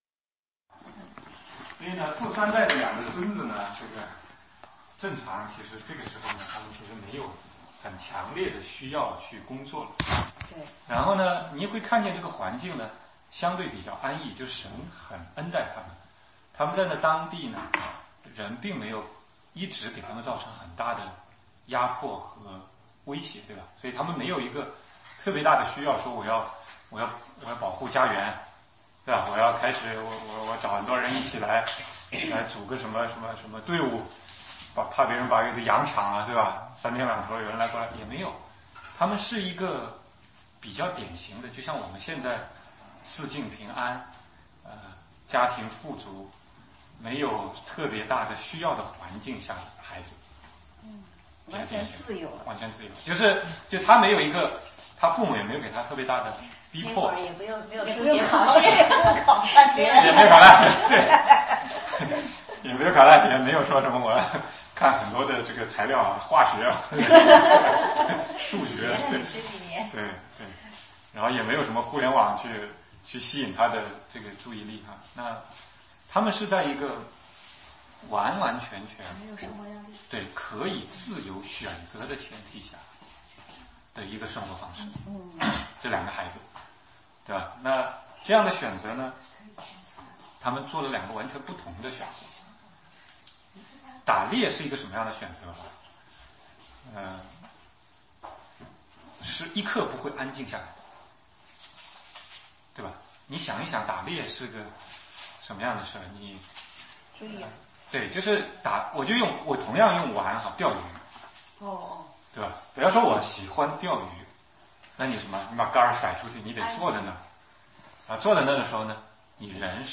16街讲道录音 - 创世纪 (雅各与以扫 )26, 27-28